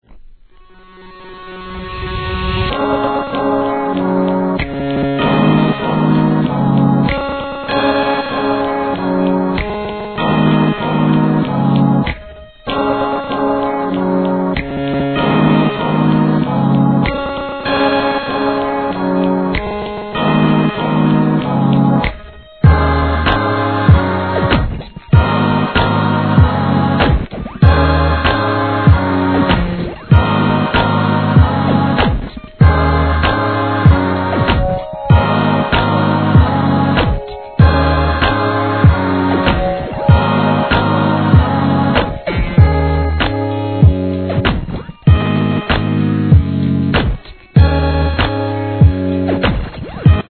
HIP HOP/R&B
センス光るエレクトロニカなブレイクビーツ!!